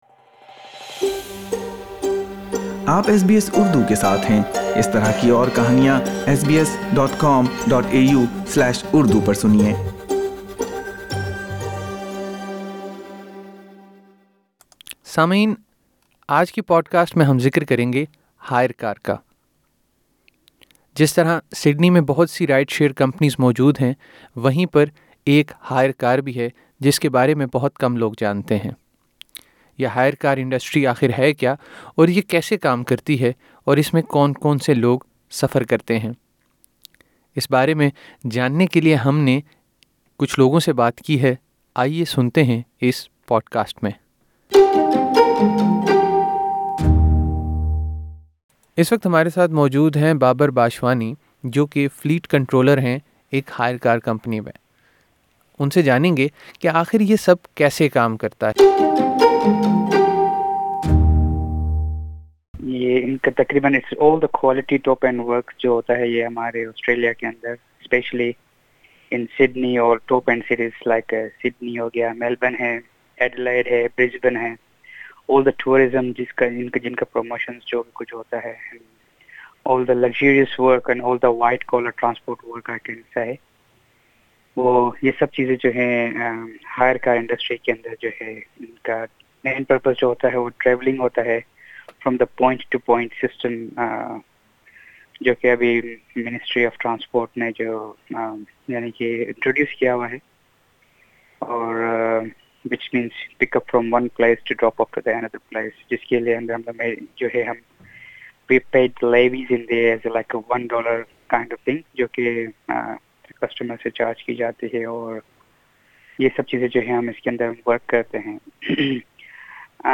آسٹریلیا میں جہاں بہت سے کاروبار ٹرانسپورٹ سے وابستہ ہیں وہیں پر ہائیر کار بھی ایک ہے- اس سلسلے میں ہم نے اس کاروبار سے منسلک افراد سے بات کی ہے- مزید سنیے اس پوڈکاسٹ میں